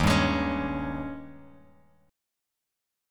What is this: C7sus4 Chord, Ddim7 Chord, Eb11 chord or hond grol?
Eb11 chord